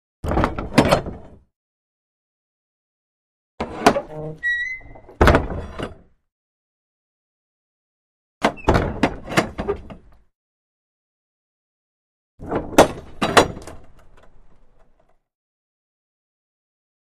Large Wooden Main Gate 3; Opens And Closes, Exterior